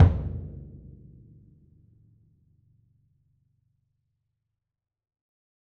BDrumNewhit_v7_rr2_Sum.wav